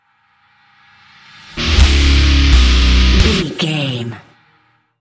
Aeolian/Minor
E♭
drums
electric guitar
bass guitar
hard rock
aggressive
energetic
intense
nu metal
alternative metal